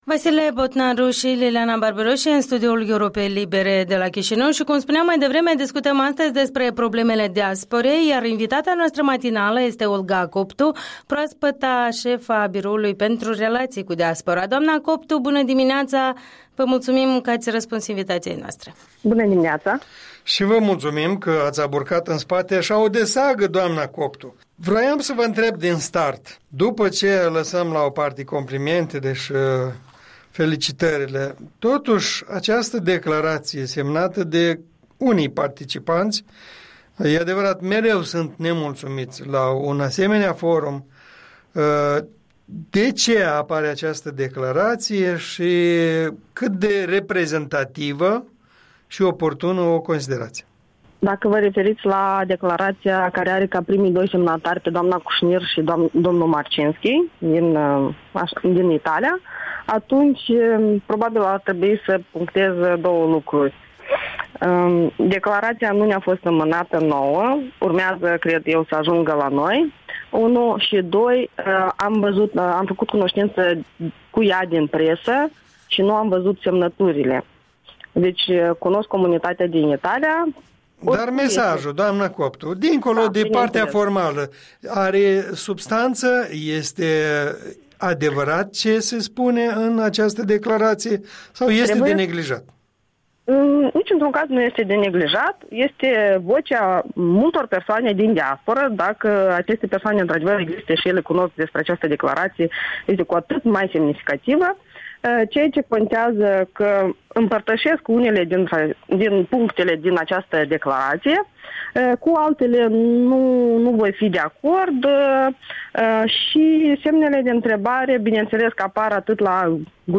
Interviul dimineții cu șefa Biroului pentru relații cu Diaspora.